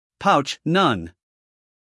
英音/ paʊtʃ / 美音/ paʊtʃ /